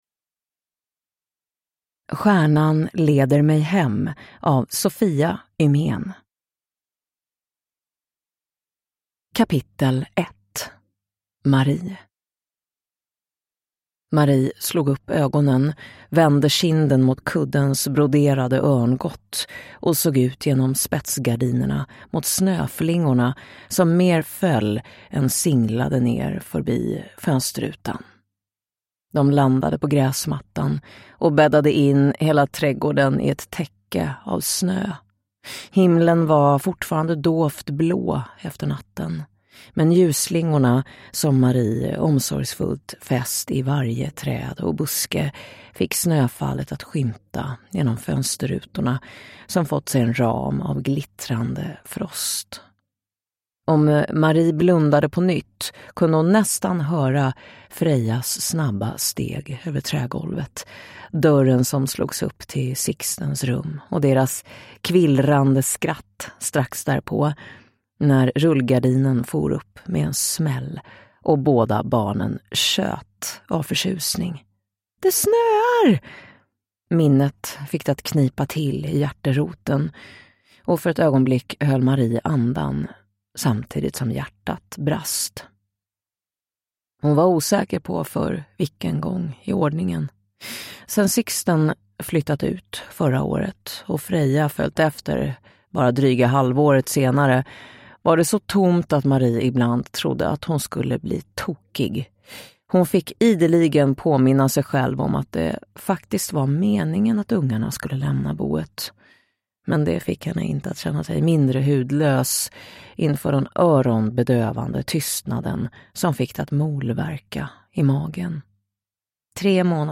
Stjärnan leder mig hem (ljudbok) av Sofia Ymén